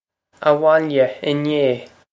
Pronunciation for how to say
ah-wohl-ya in-ay.
This is an approximate phonetic pronunciation of the phrase.